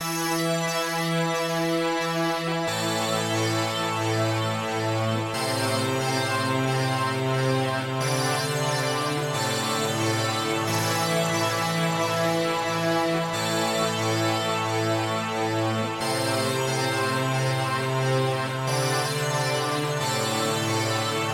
描述：哥特式垫环
Tag: 70 bpm Ambient Loops Pad Loops 1.15 MB wav Key : E